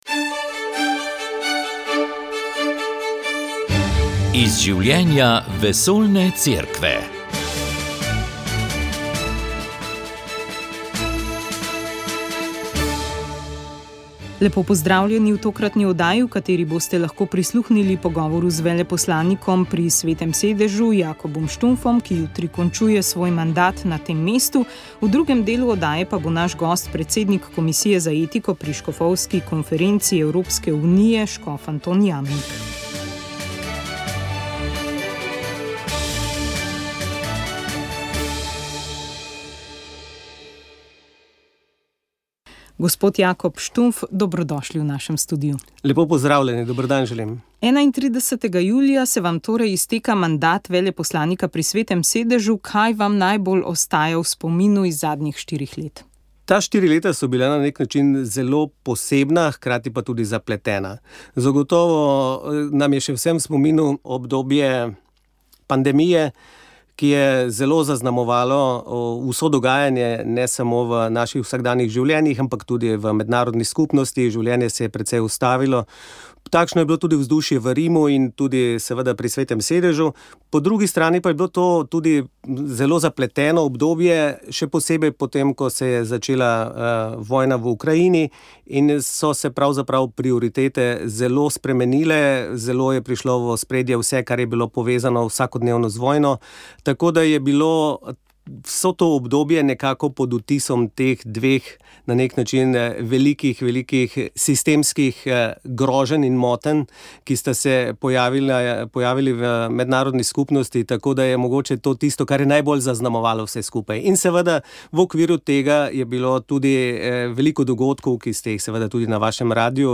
Vizija Cerkve v prihodnjih desetletjih - predavanje škofa Andreja Sajeta